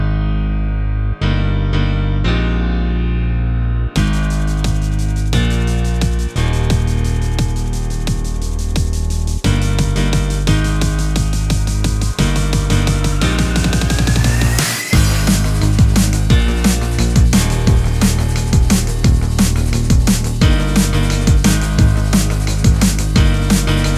no Backing Vocals Dance 4:03 Buy £1.50